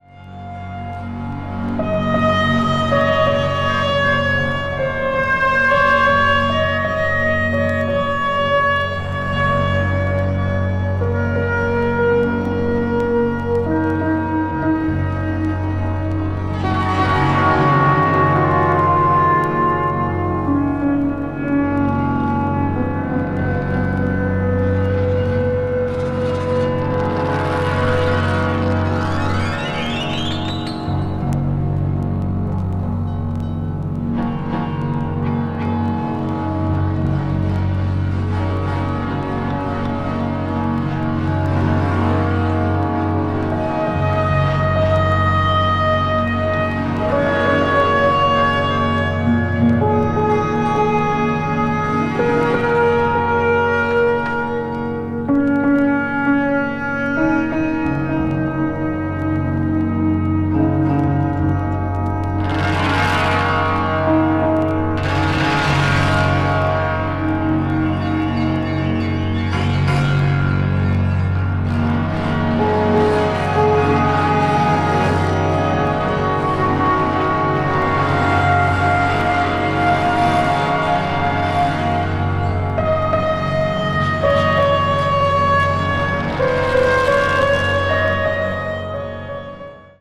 is a shimmering reverberation of the highest order.